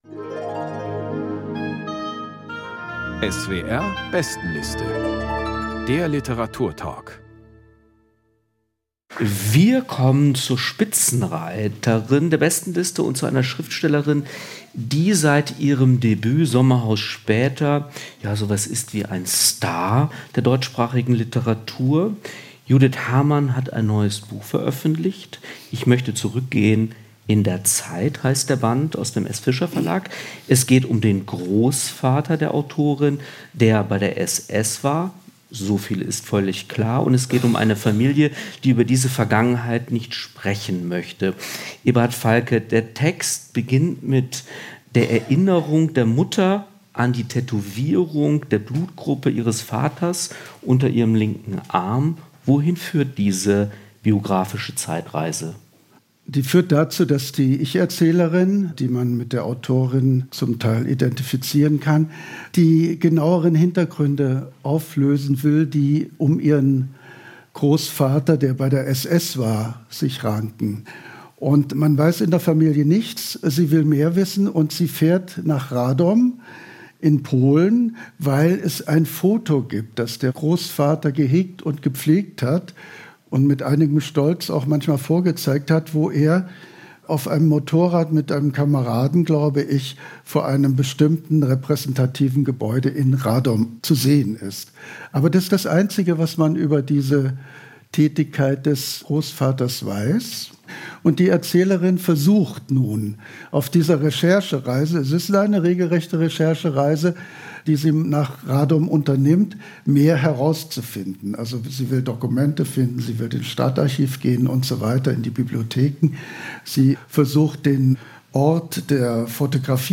Judith Hermann: Ich möchte zurückgehen in der Zeit | Diskussion und Lesung ~ SWR Kultur lesenswert - Literatur Podcast